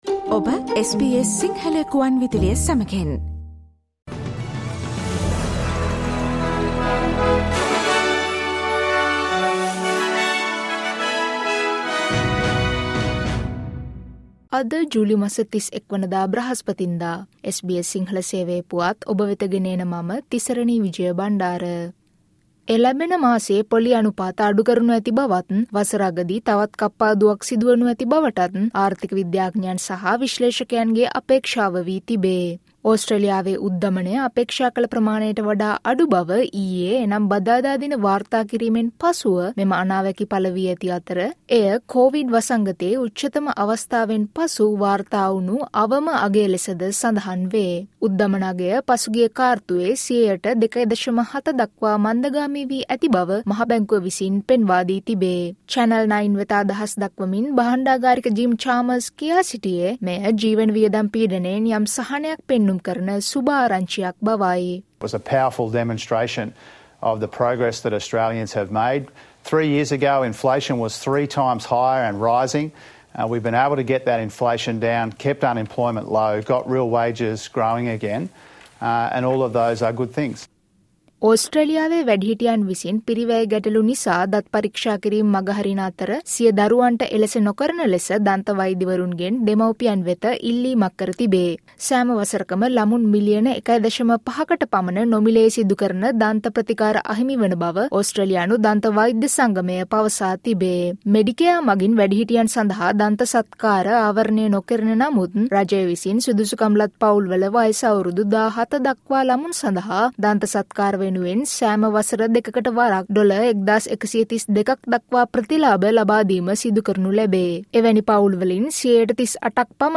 ඕස්ට්‍රේලියාවේ පුවත් සිංහලෙන් දැනගන්න, ජූලි මස 31වන දා SBS සිංහල Newsflashවලට සවන් දෙන්න